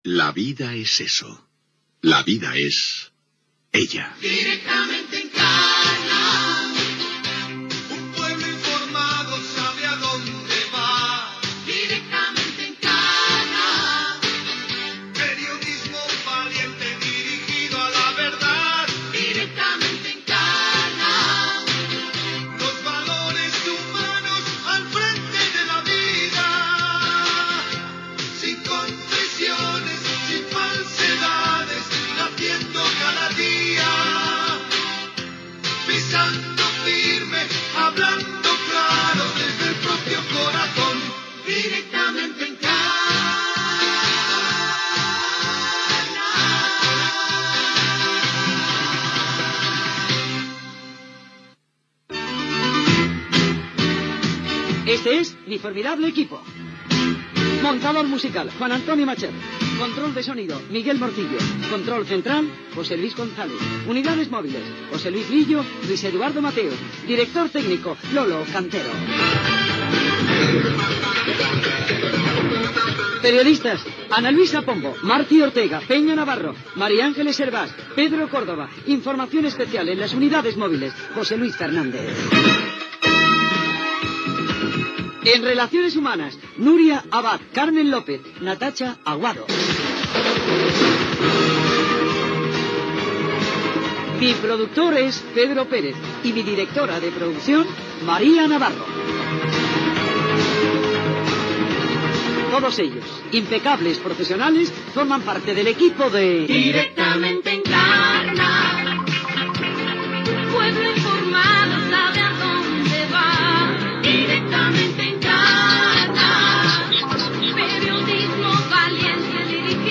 Cançó del programa, equip i cançó del programa
Info-entreteniment